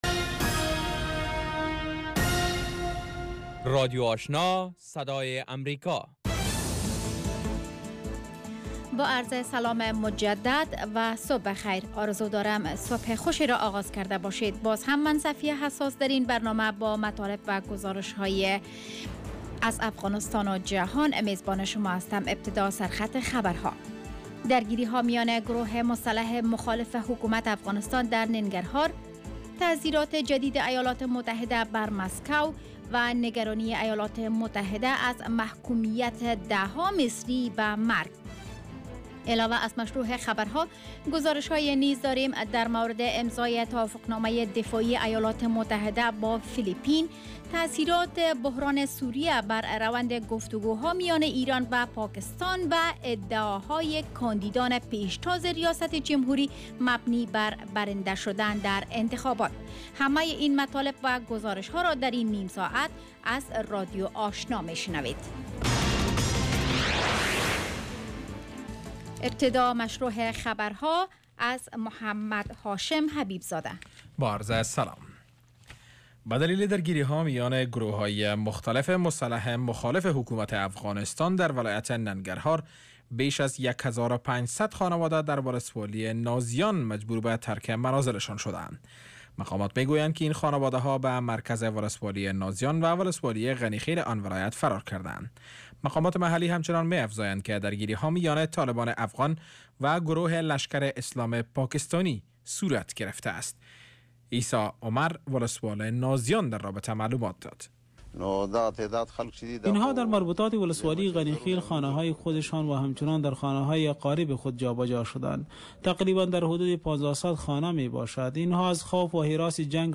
morning news show second part